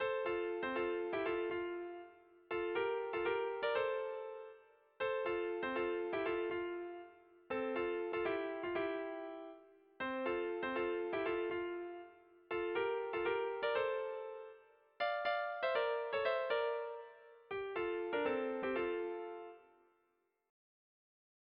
Erlijiozkoa
Zortziko txikia (hg) / Lau puntuko txikia (ip)
A-A2-A-B